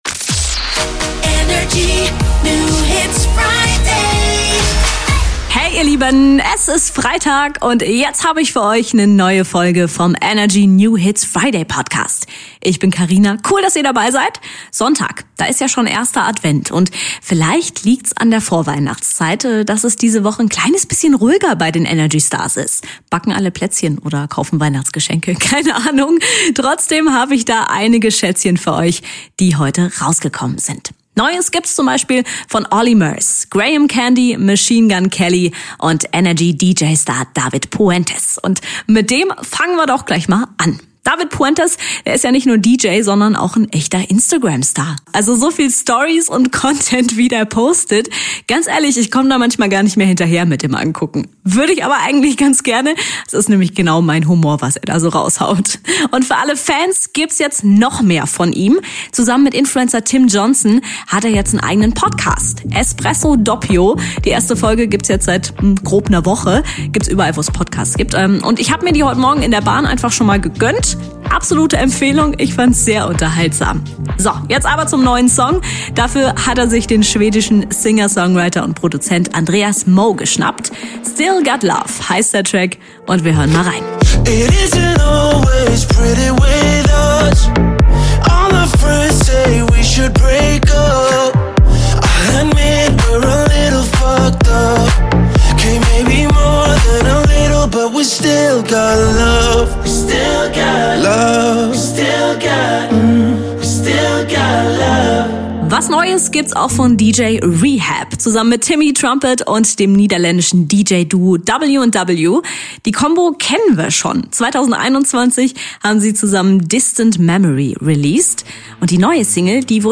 stellt brandneue hitverdächtige Songs vor.